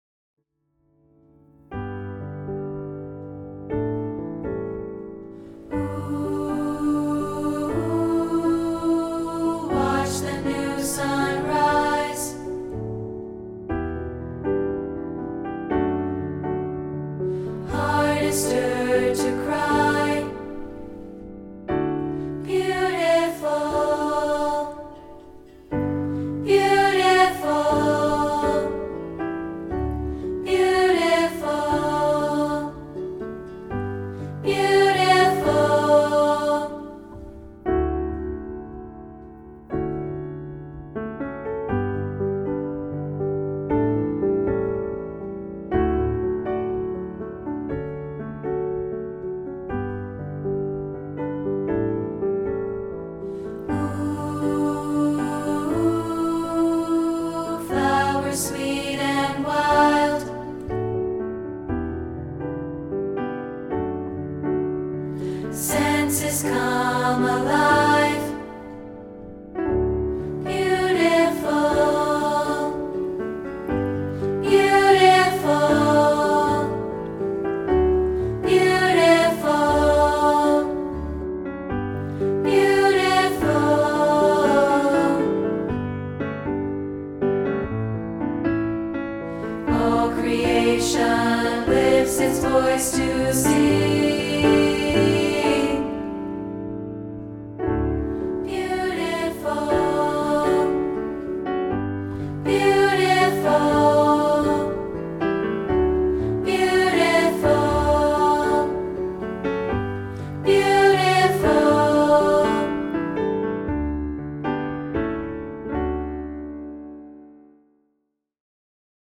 including this rehearsal track of part 3, isolated.